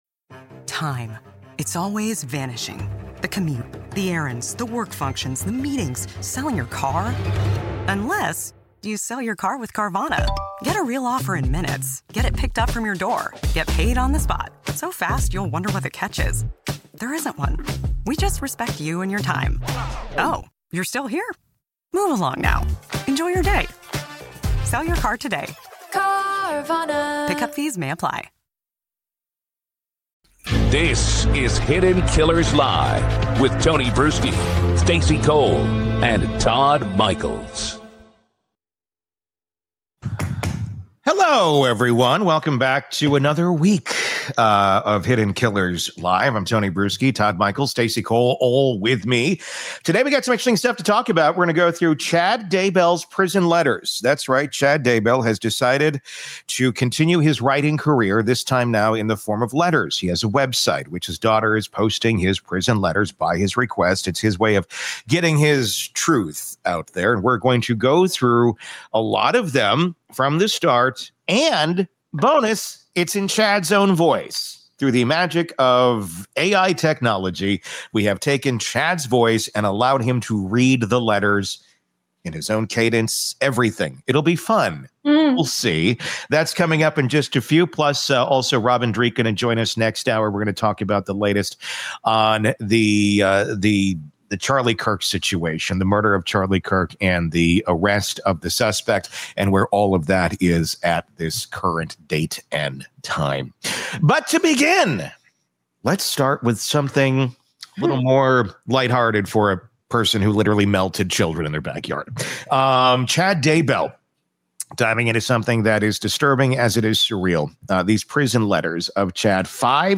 With the help of AI technology, we hear Chad’s prison letters read in his own eerie cadence — giving listeners a chilling glimpse into how he wants the world to see him.